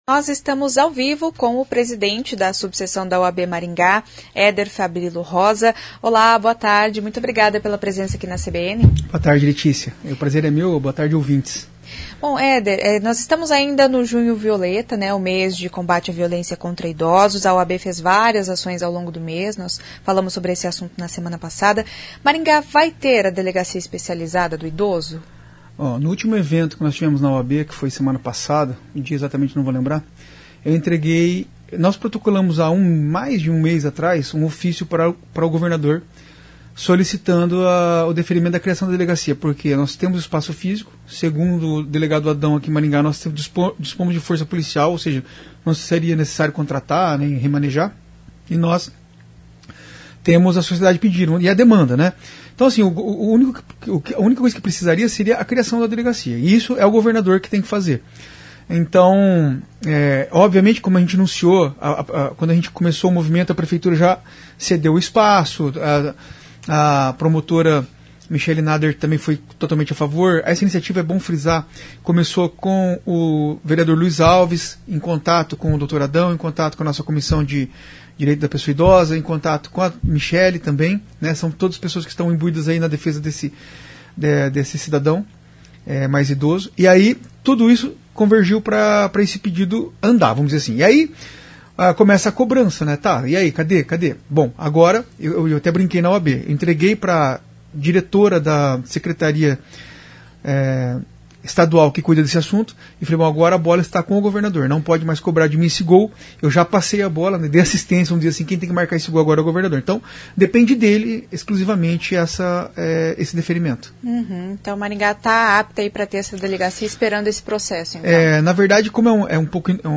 em entrevista à CBN.